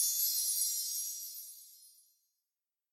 snd_fairydie.ogg